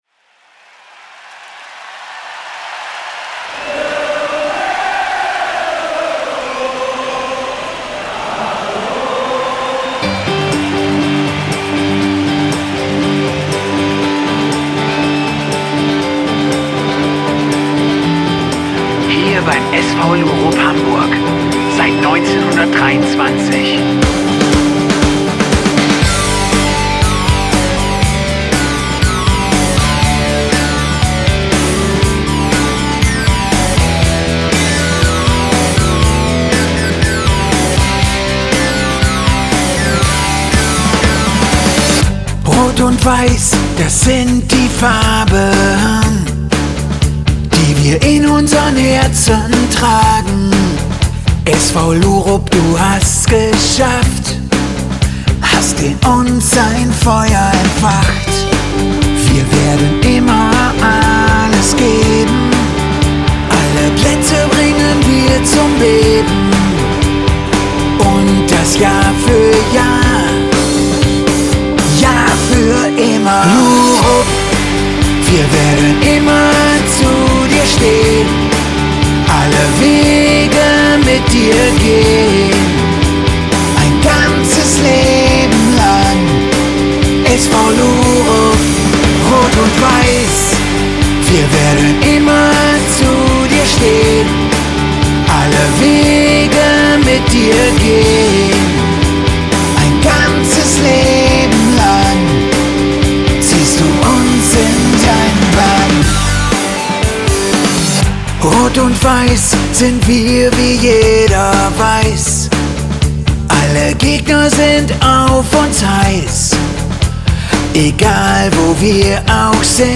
Hymne